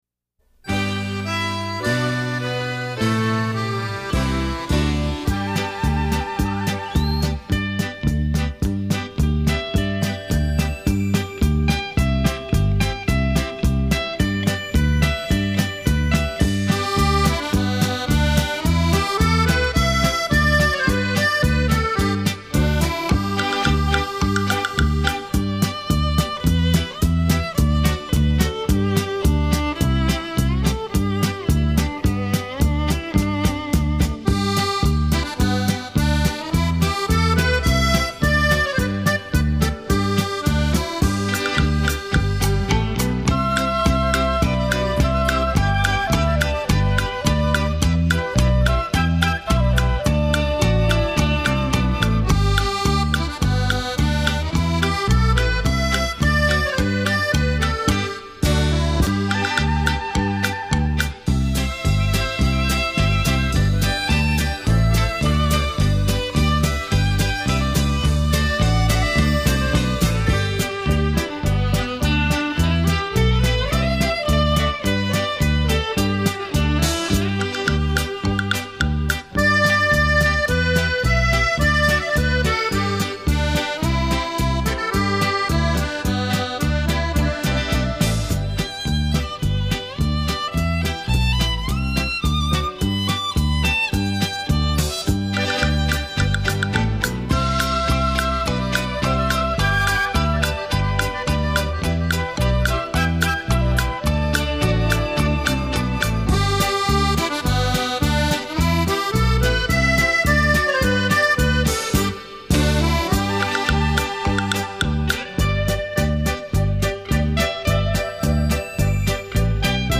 本公司最新出品器樂之旅為當今國內外名家樂器演奏精華，技巧細緻，風格獨特，